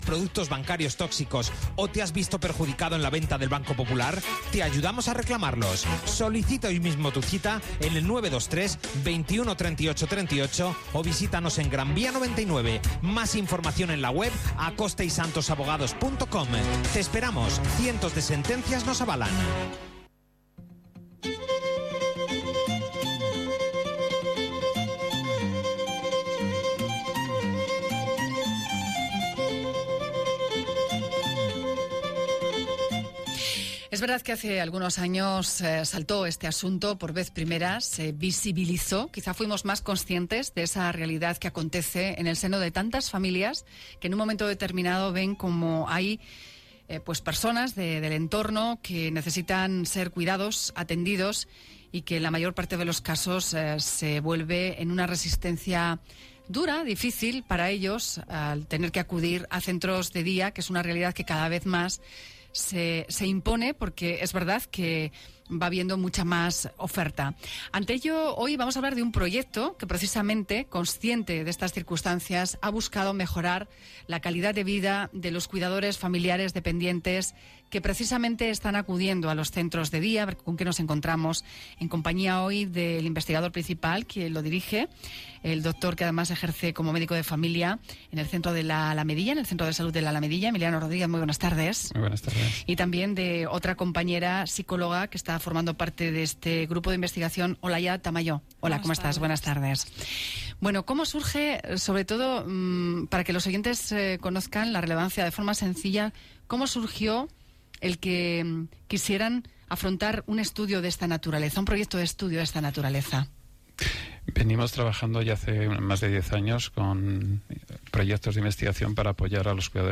Entrevista .onda cero.mp3